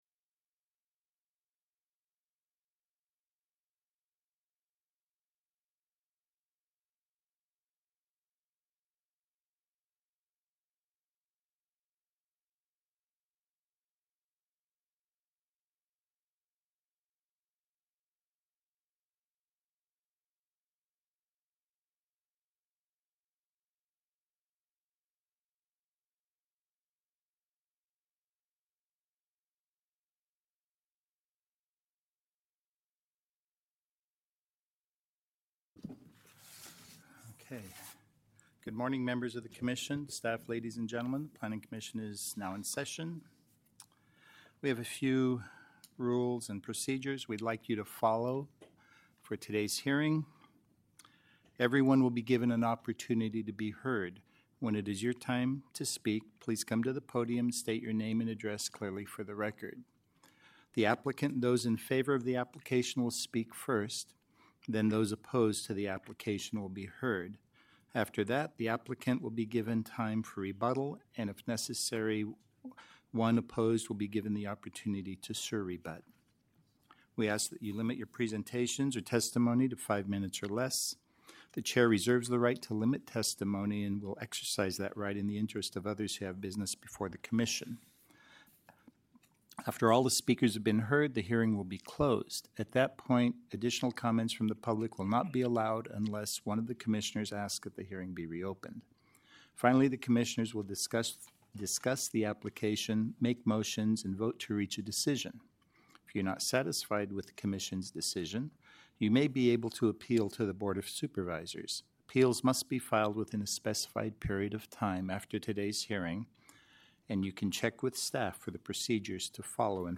November 14, 2024 Fresno County Planning Commission Hearing